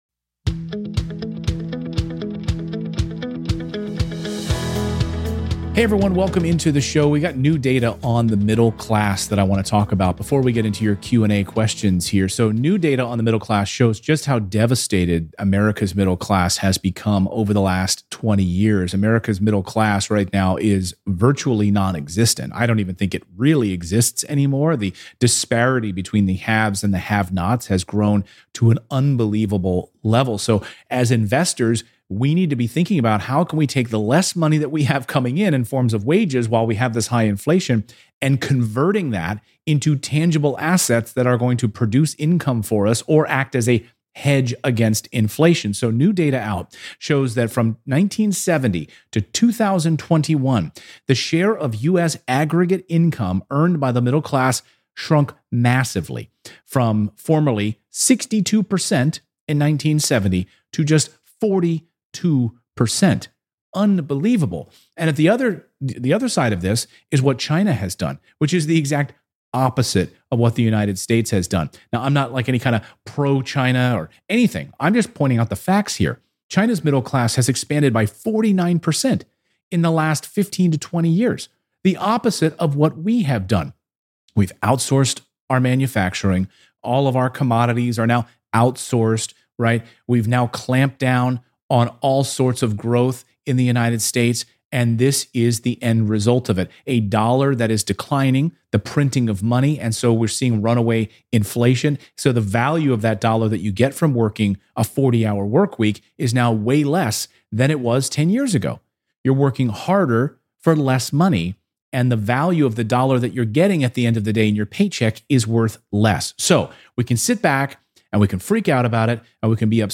Today's first caller has contacted 30 banks to find a HELOC on an investment property, and still came up empty handed.